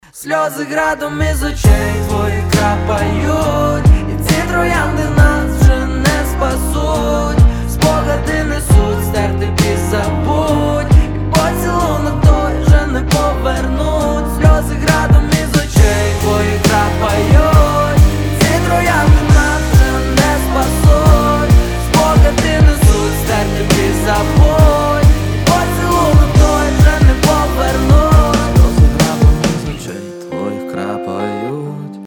• Качество: 320, Stereo
лирика